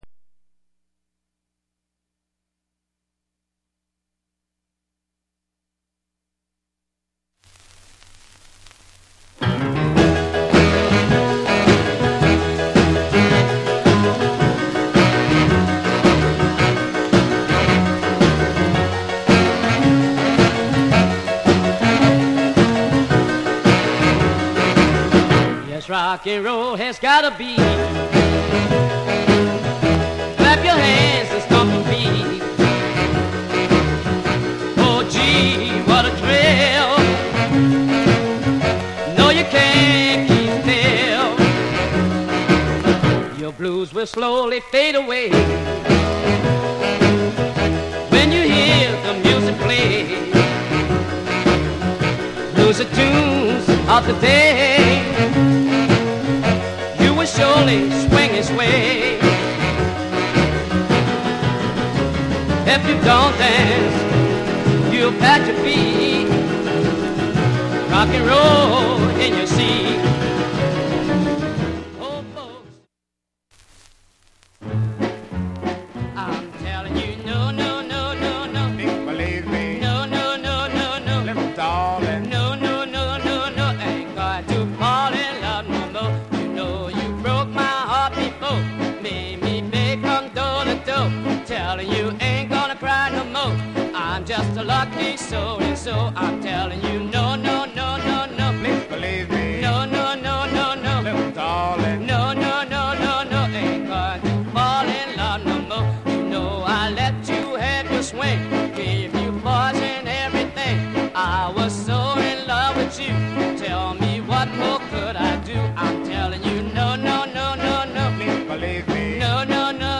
US R&B / Black Rocker / Jump US盤
オリジナル盤7インチ